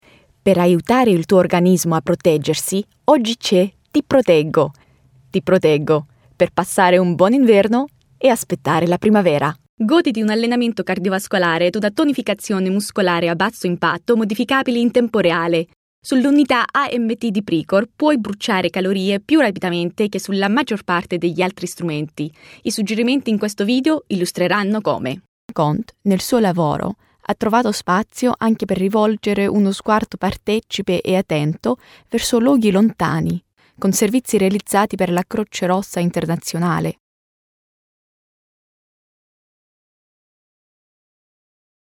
Italian Montage: